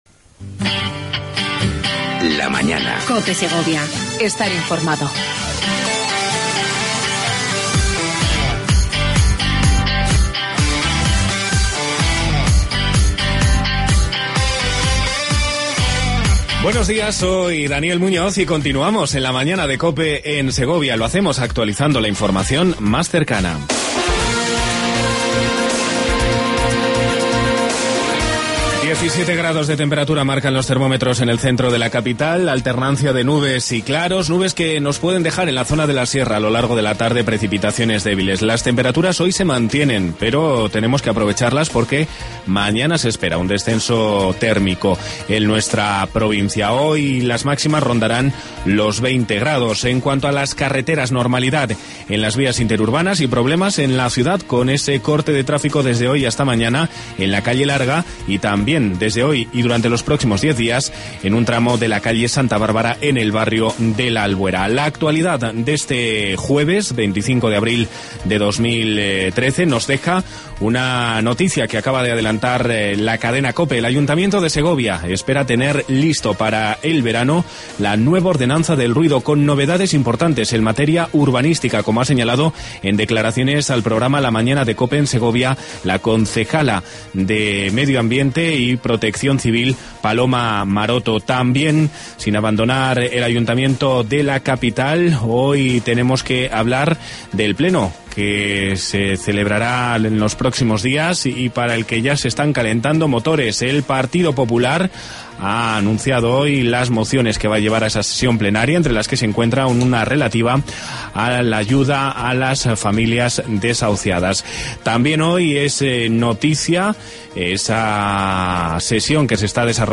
Hablamos con su alcalde Jaime Pérez,